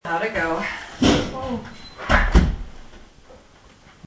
Loft – 2:40 am
Shower curtain falls
loft-240-am-shower-curtain-falling.mp3